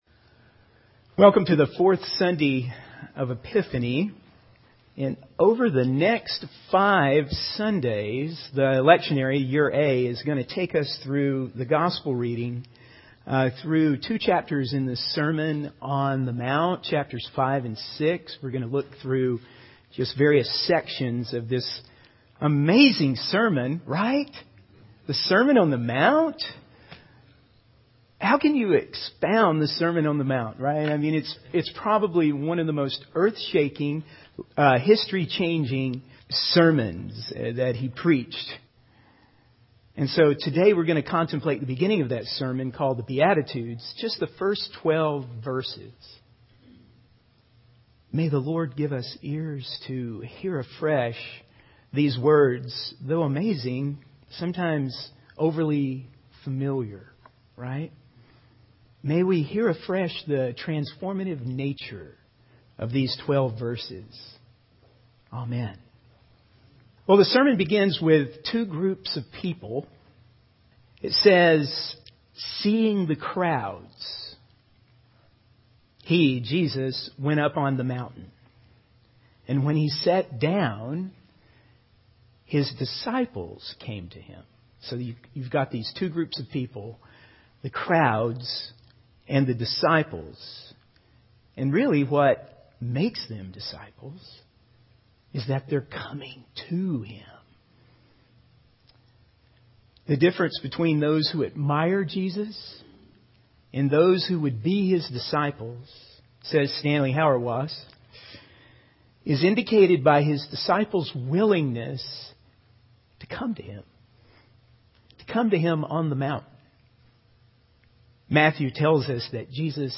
In this sermon, the preacher focuses on the beginning of the Sermon on the Mount, specifically the Beatitudes.